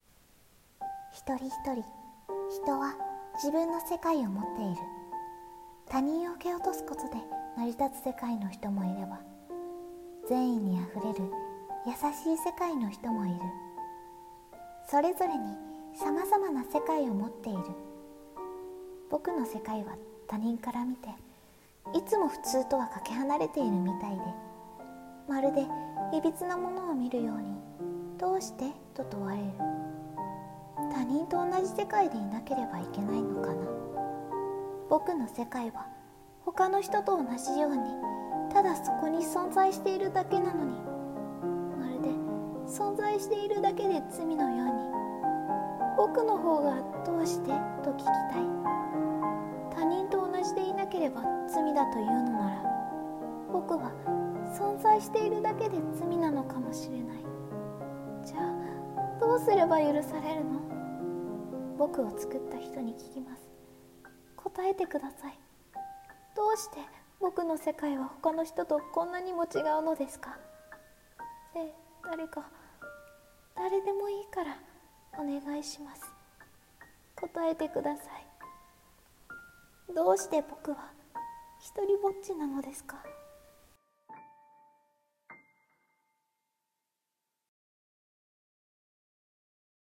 【声劇】ひとりぼっちの世界【朗読】